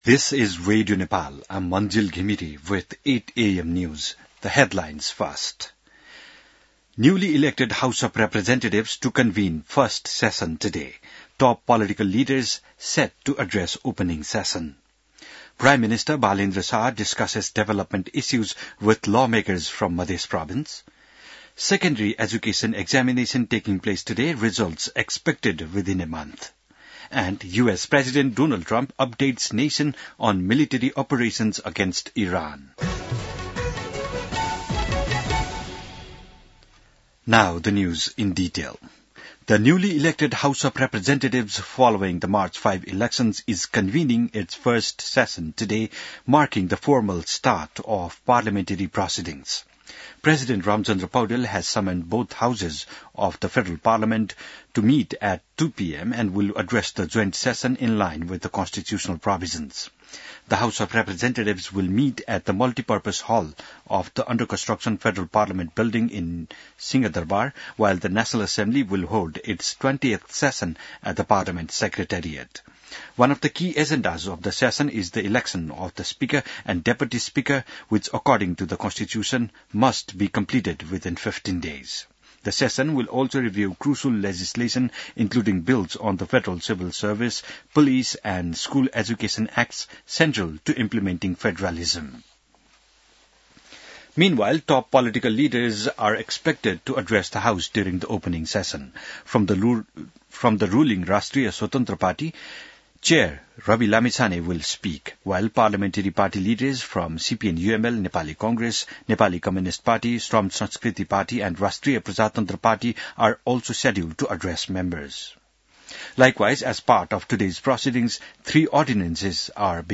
बिहान ८ बजेको अङ्ग्रेजी समाचार : १९ चैत , २०८२